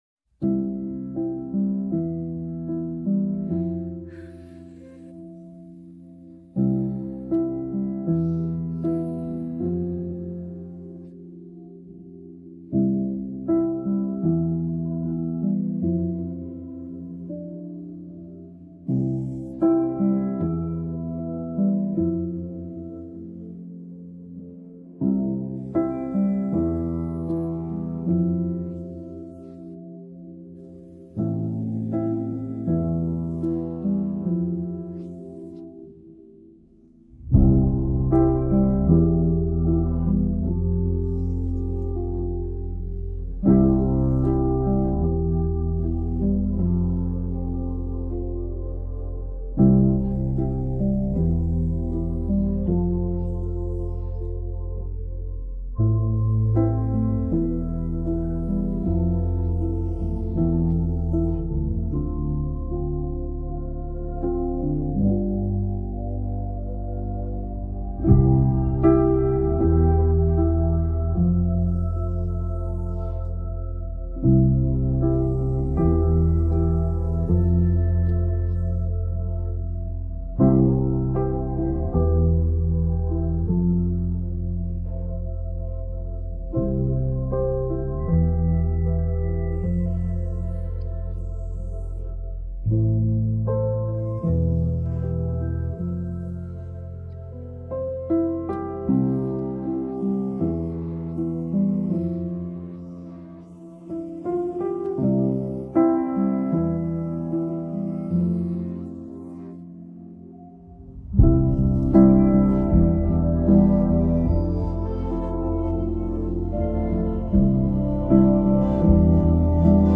this is the instrumental for it